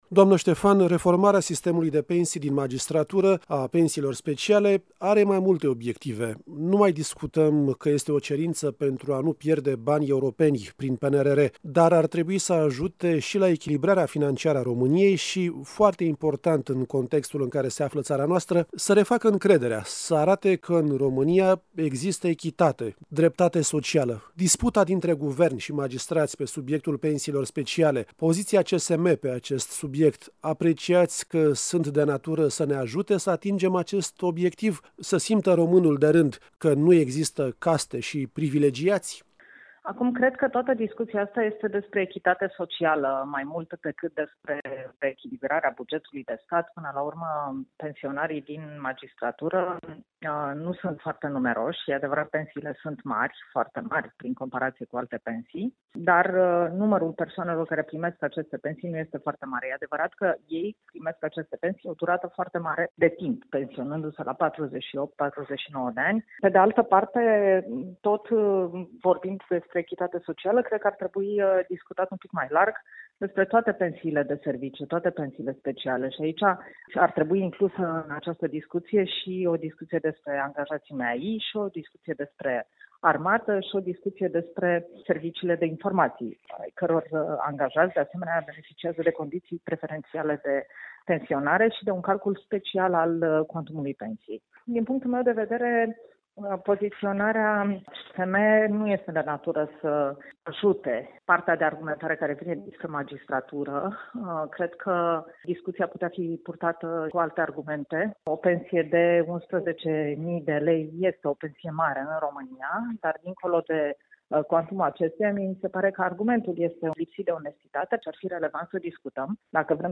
Ce ecou au aceste declarații în societate și cum văd această dispută specialiștii în Justiție, dar care nu fac parte din sistem? Răspunsurile, în interviul următor